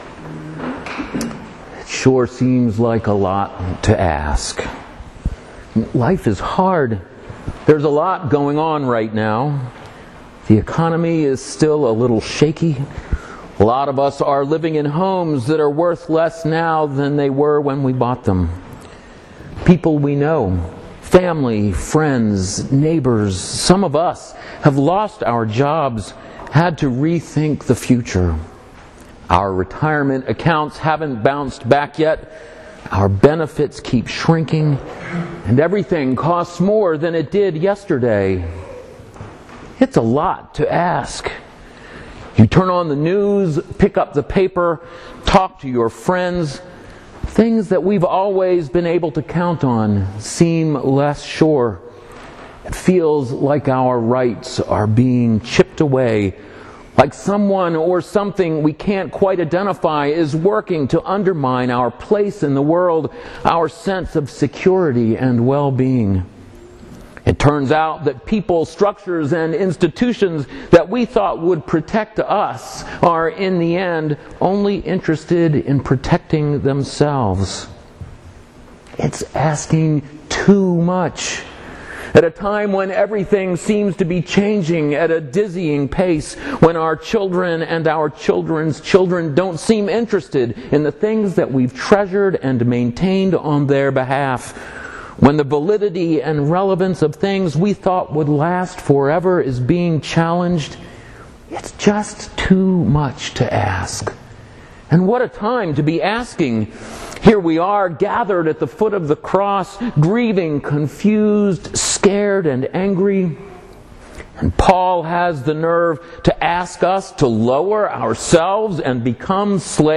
There is Blood on our Hands: A Sermon for Palm Sunday
sermon-palm-sunday-2013.mp3